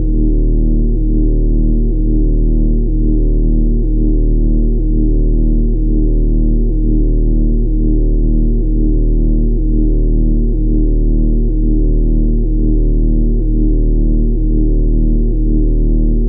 scifi_nodes_ambience_engine.ogg